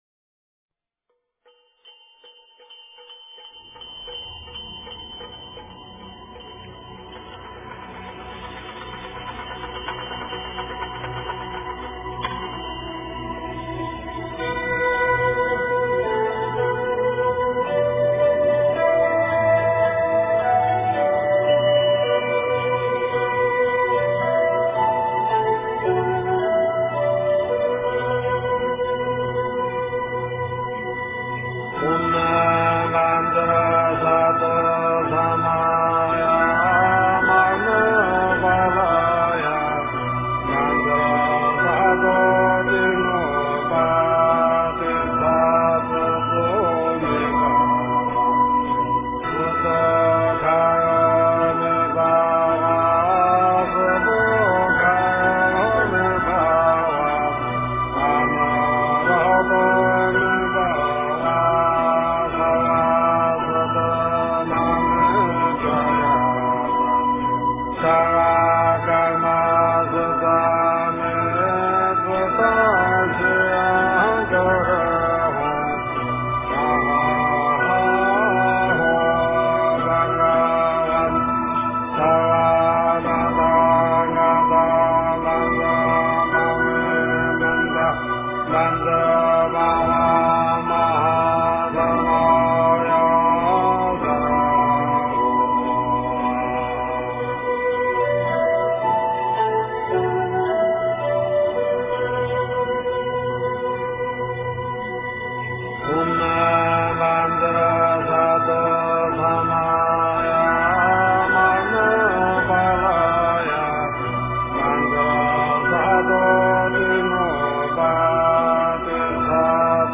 真言
佛音
佛教音乐